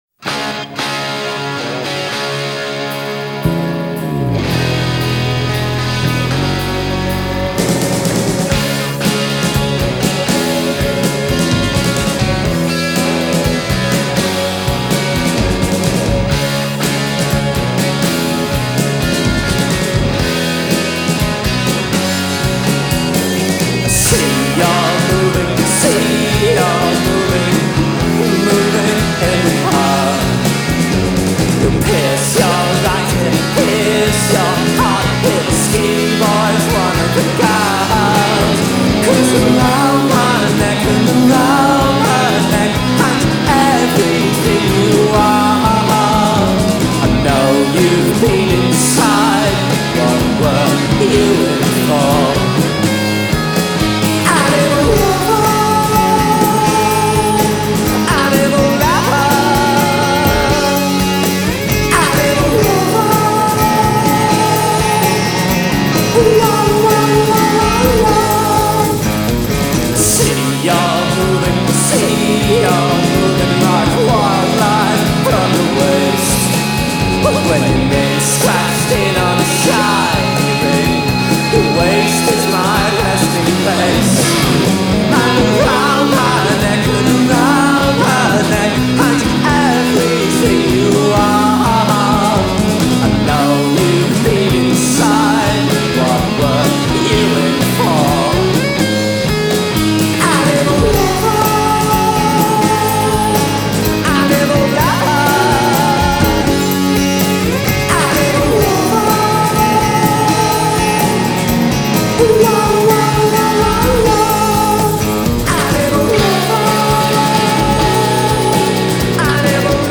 Жанр: Britpop, alternative rock, glam rock
тяжелое гитарное звучание